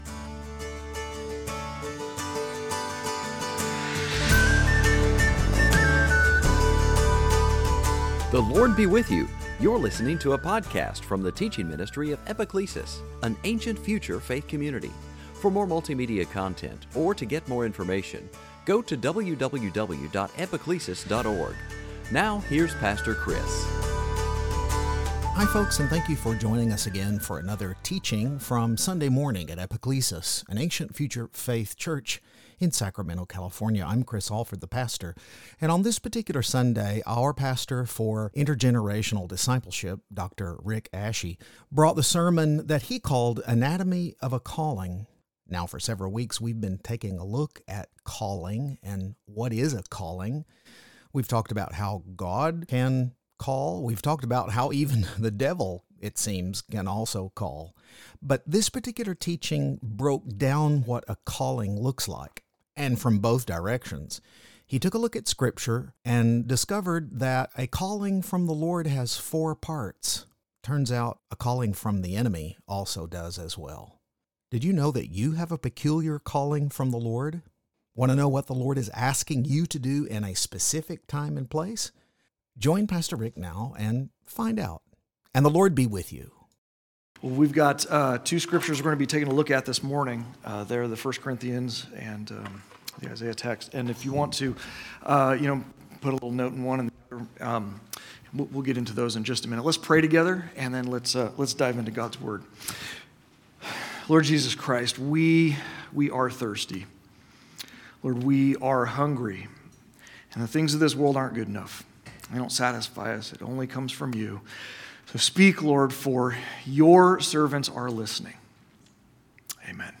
Service Type: Lent